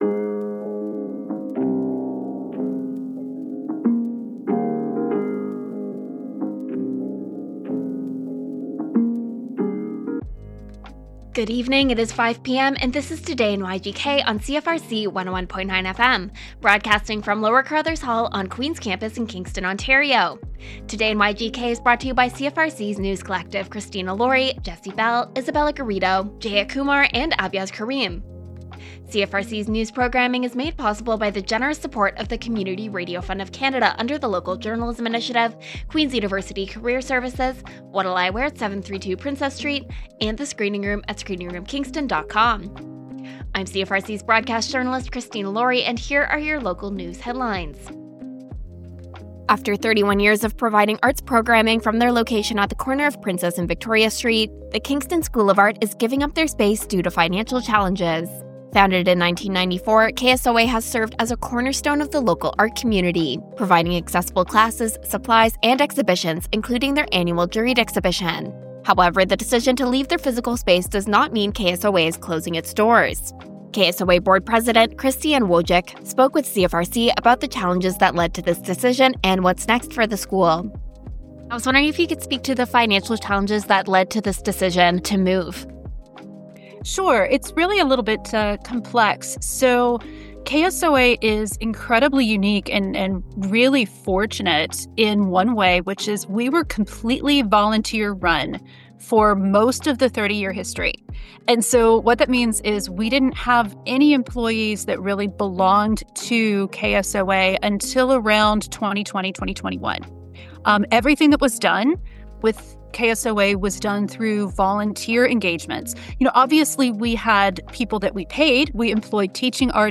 Local news updates with CFRC’s News Team.